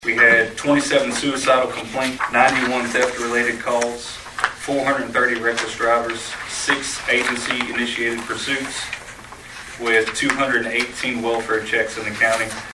And that’s according to Sheriff Aaron Acree, who Monday night at fiscal court gave a comprehensive year-in-review to Trigg County’s magistrates and their judge-executive, Stan Humphries.